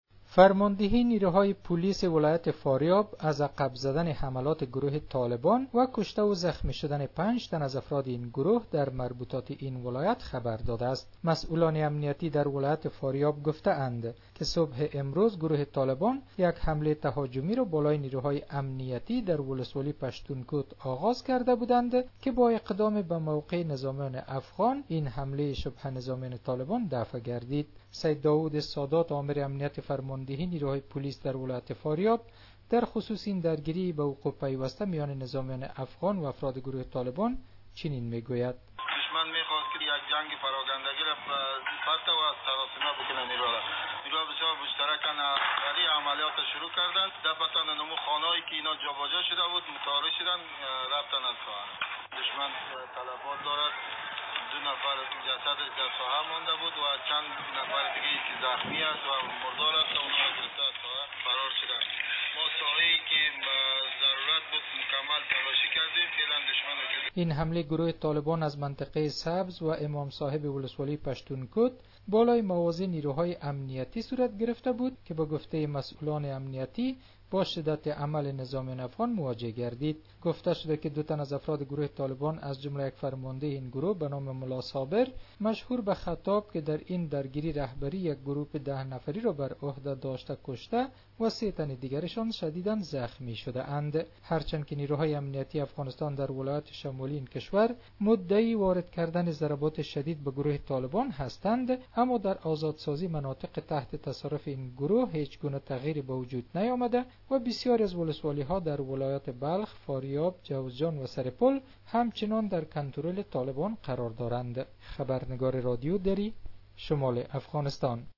به گزارش خبرنگار رادیو دری